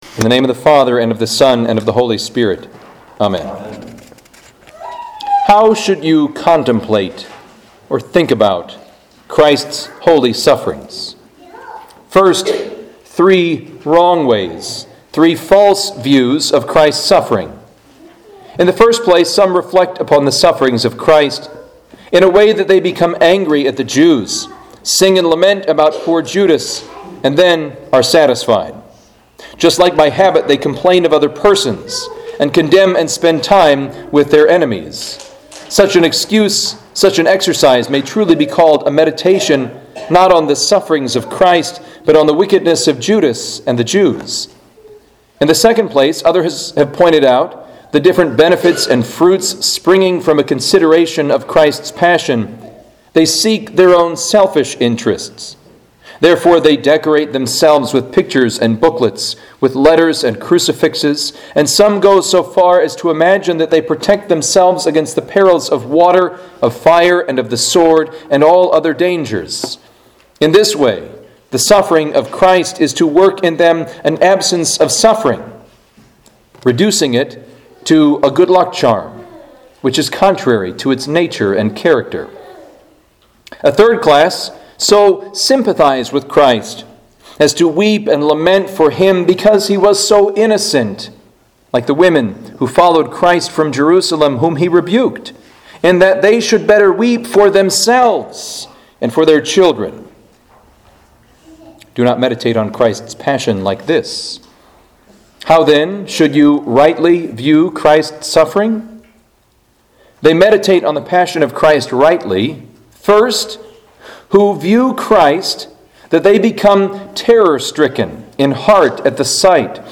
Good Friday Chief Service
Home › Sermons › Good Friday Chief Service